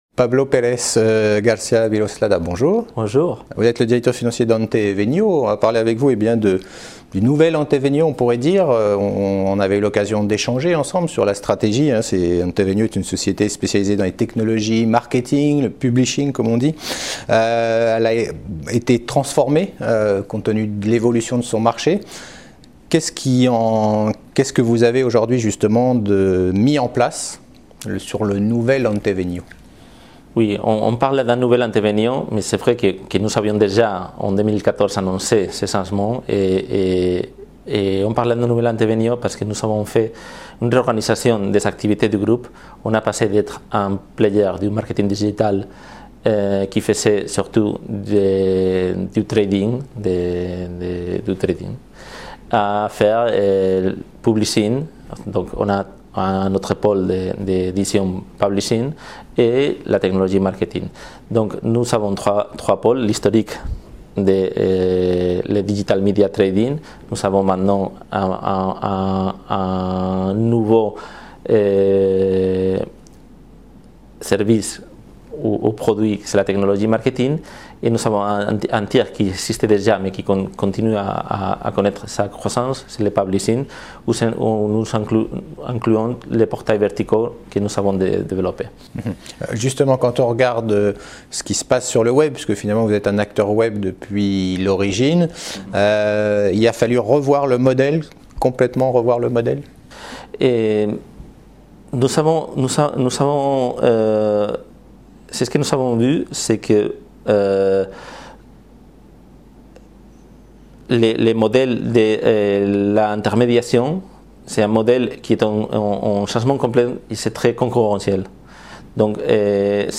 La Web Tv partenaire media du SmallCaps Event organisé par CF&B Communication à Paris pour des interviews de dirigeants.